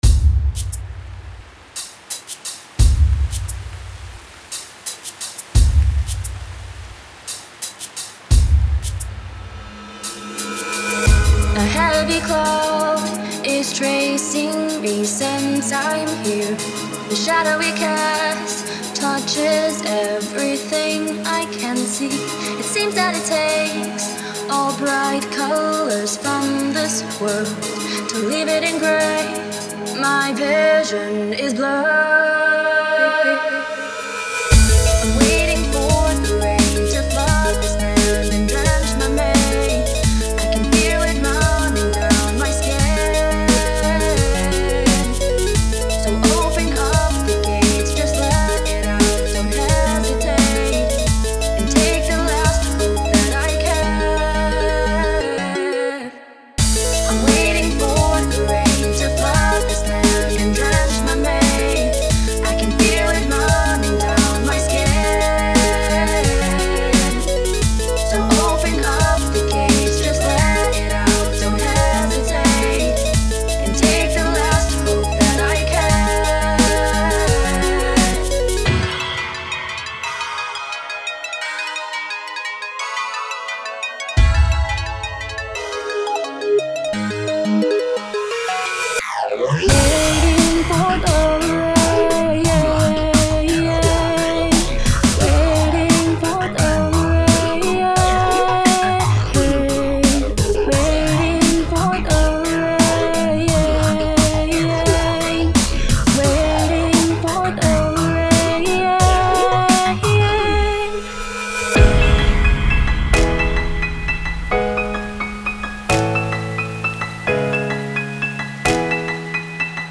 The kick is kinda echoey.
It gave it a sort of classic 90's acid breaks feel.